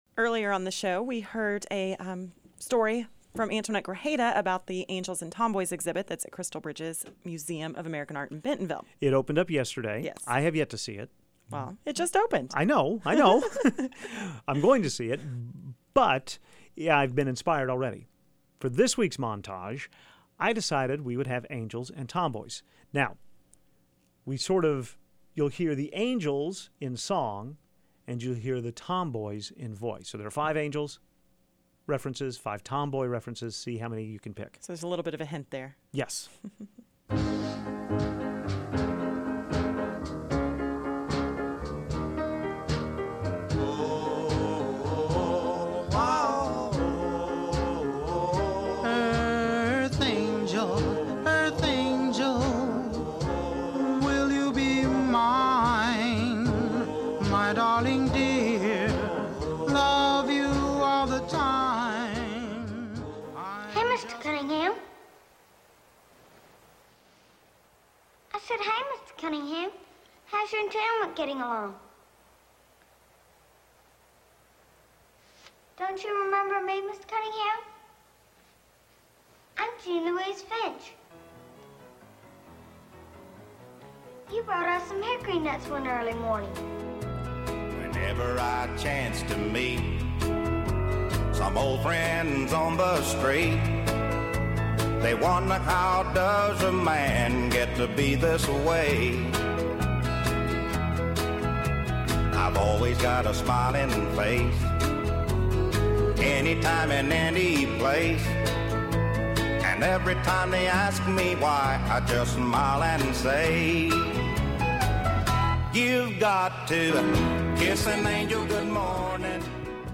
Here are the ten clips of angels and tomboys heard in today’s montage, alternating angels with tomboys. 1) Earth Angel from the Penguins. 2) Scout (Mary Badham) saves Atticus at the courthouse in To Kill a Mockingbird. 3) The great Charley Pride sings To Kiss an Angel Good Morning. 4) Karen Allen’s Marion Ravenwood punches Harrison Ford’s Indiana Jones in Raiders of the Lost Ark. 5) John Prine’s wonderful Angel From Montgomery in the very capable hands of Bonnie Raitt. 6) Blair (not a tomboy) learns to appreciate Jo on The Facts of Life. 7) Ray Charles singe Seven Spanish Angels. 8) Peppermint Patty, an underrated character, sits with Charlie Brown. 9) Tatum O’Neal (in an Oscar-winning performance) argues with real-life father Ryan O’Neal in Paper Moon. 10) Lucinda Williams’ Drunken Angel.